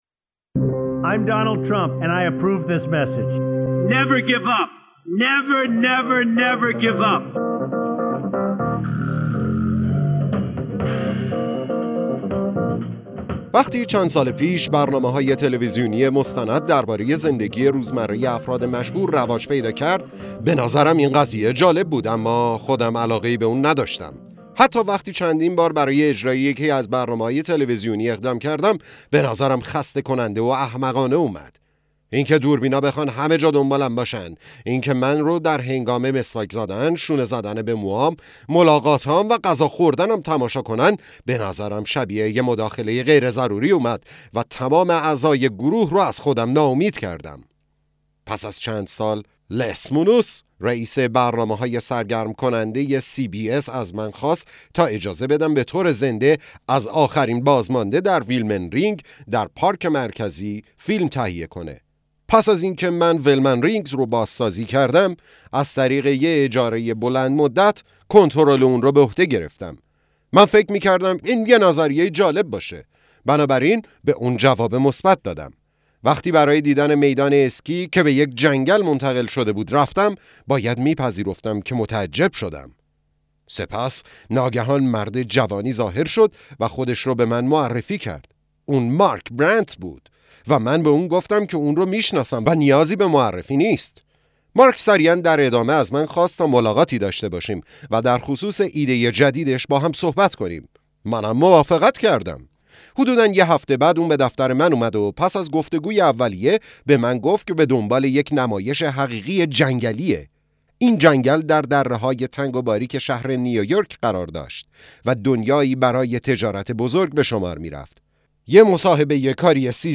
کتاب صوتی هرگز تسلیم نشو زدونالد ترامپ
دمو-کتاب-صوتی-تسلیم-نشو.mp3